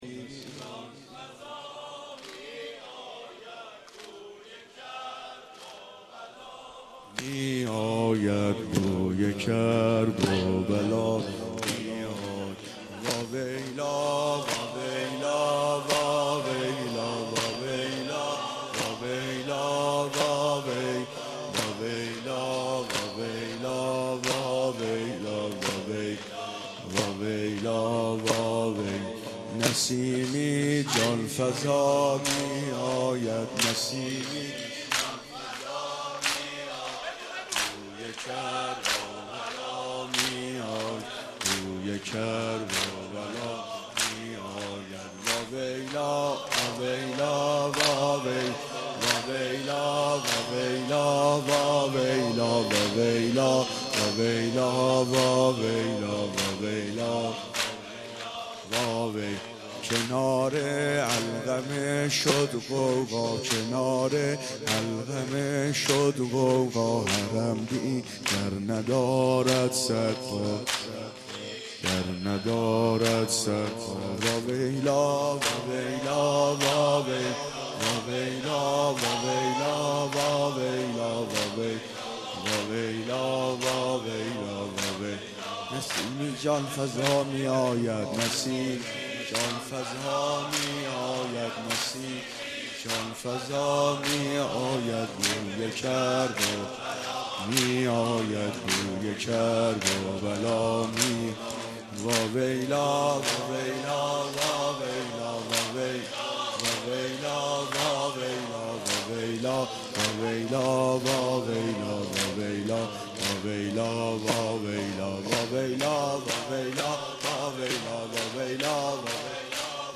حاج عبدالرضا هلالی/مراسم هفتگی جمعه 11 تیر(95)
روضه امام حسین(ع)